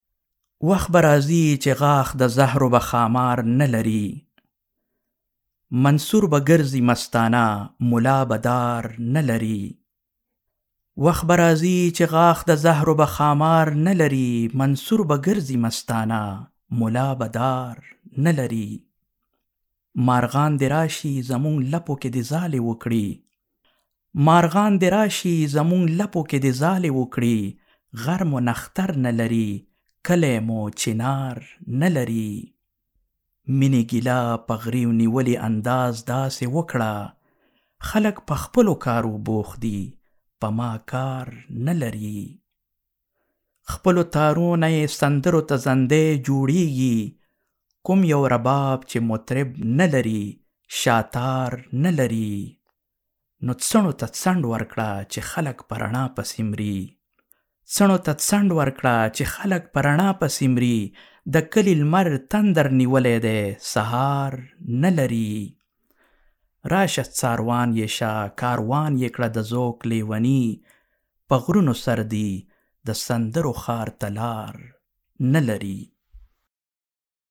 Male
Adult